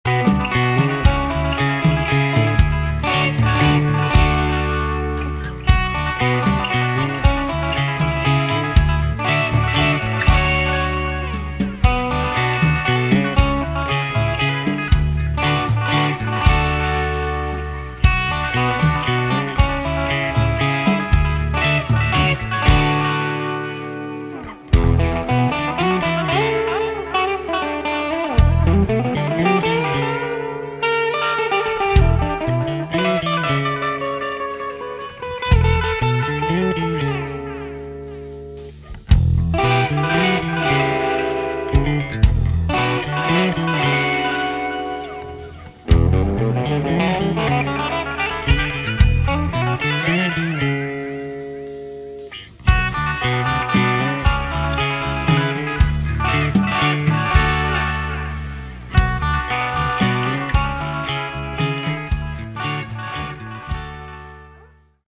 Category: Prog Rock
guitars, vocals
drums
bass